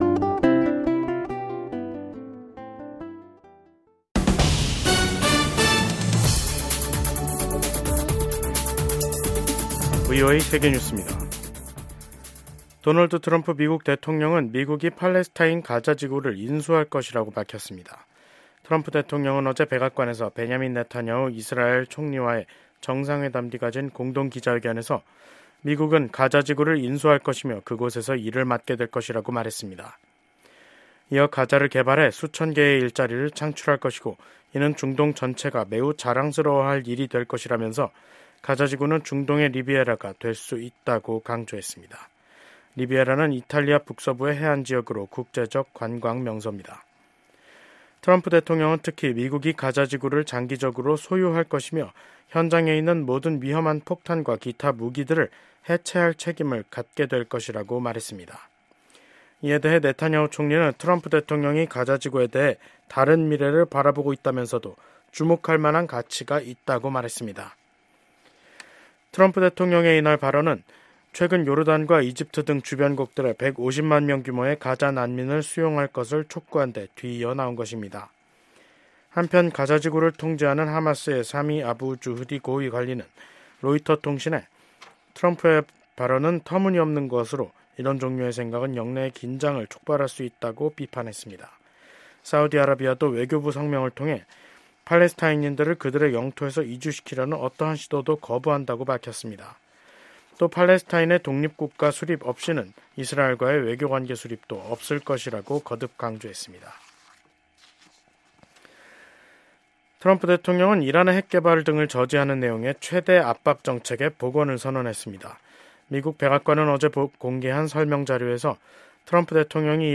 VOA 한국어 간판 뉴스 프로그램 '뉴스 투데이', 2025년 2월 5일 2부 방송입니다. 러시아에 파견돼 상당수 사상자가 발생한 북한 군이 쿠르스크 전선에서 최근 일시 퇴각했다는 소식이 전해지면서 추가 파병이 임박한 게 아니냐는 관측이 나오고 있습니다. 북대서양조약기구(나토. NATO)가 북한의 러시아 파병 증원설과 관련해 양국에 국제법 위반 행위를 즉각 중단할 것을 촉구했습니다.